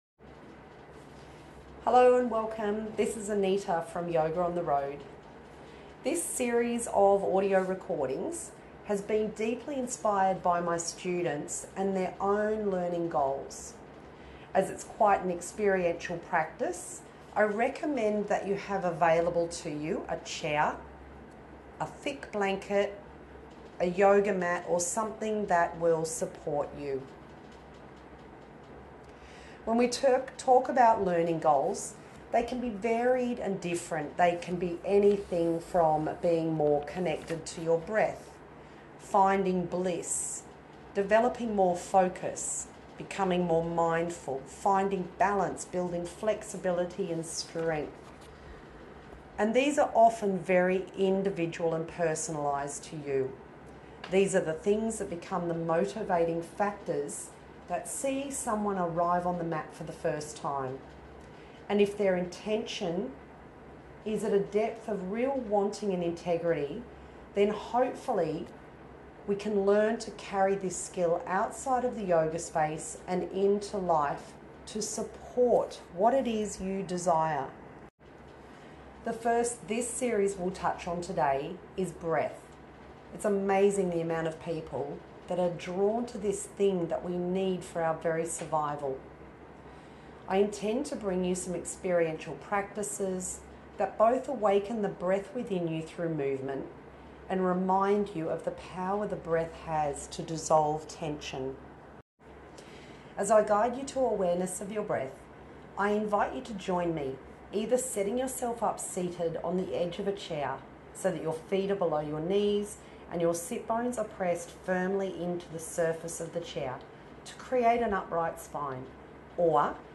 Your Time for Renewal: A Yoga Breathing Audio Series Reconnect with your inner self through Your Time for Renewal, a breathing-focused yoga audio series. Designed for beginners and advanced beginners, these sessions guide you in cultivating awareness and calm through intentional breathwork.